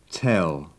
Удвоенные согласные буквы в пределах одного слова читаются как один звук: tell [], bell [].
1. В конце слова и перед согласными звук [] имеет «темный» оттенок.
tell.wav